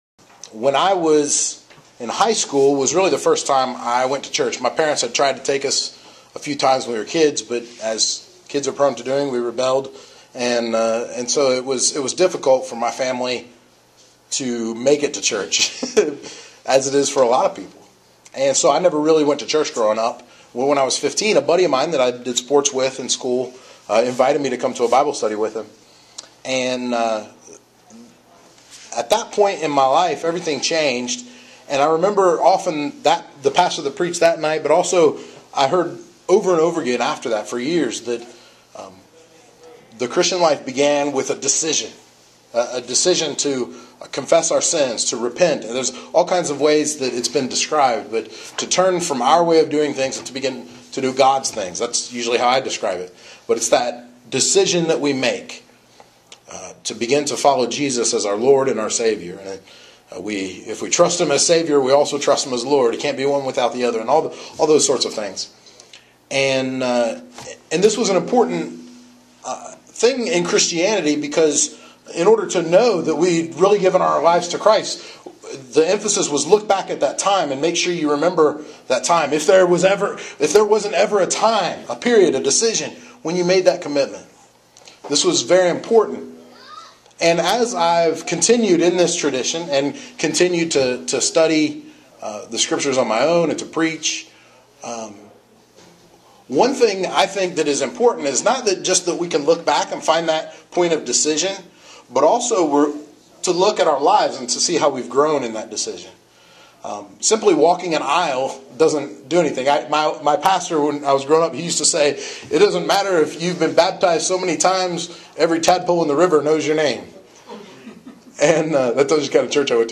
The third of four sermons through the New Testament Book of Philippians from the Spring of 2014